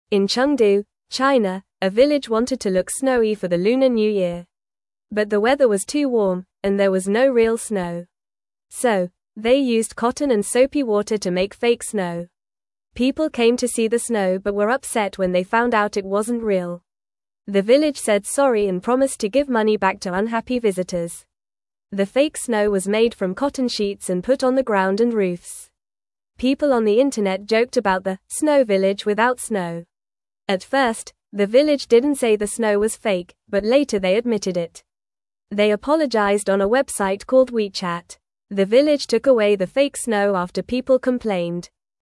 Fast
English-Newsroom-Beginner-FAST-Reading-Village-Makes-Fake-Snow-for-Lunar-New-Year-Fun.mp3